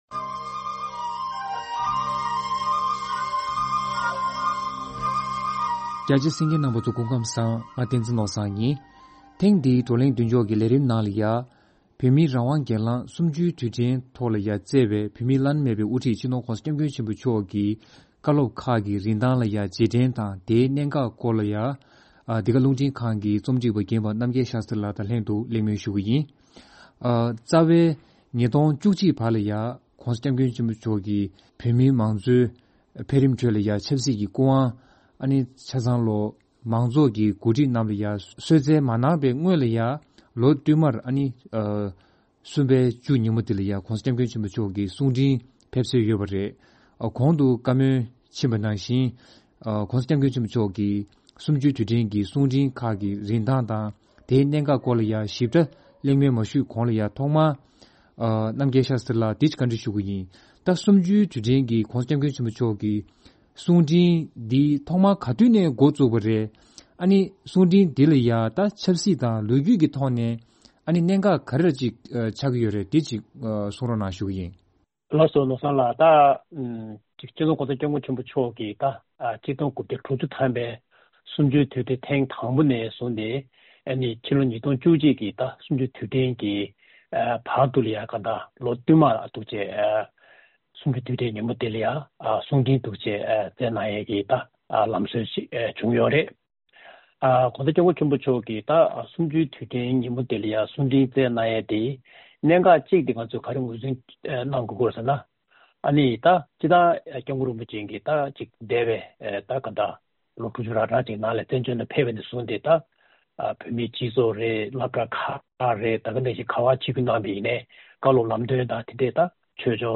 Sunday Table Talk – Discussion with Senior Editor on the Importance of March 10th